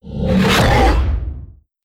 demon_attack.wav